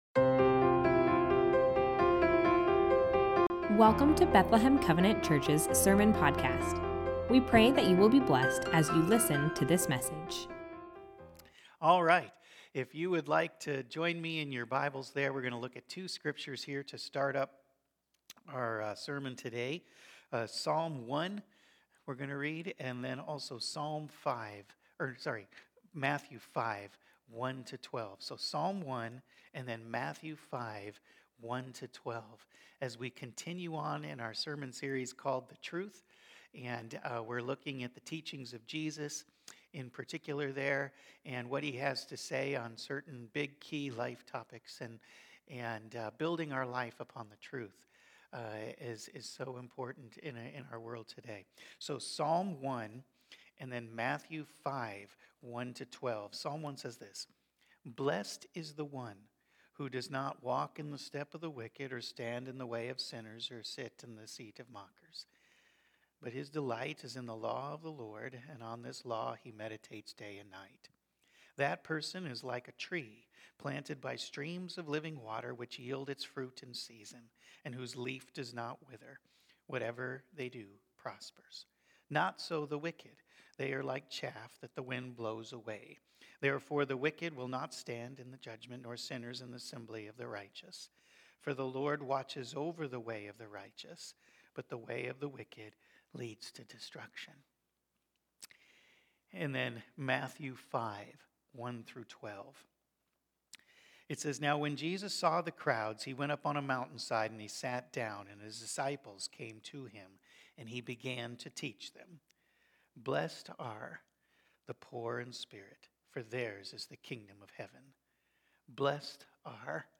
sermon-1.23.mp3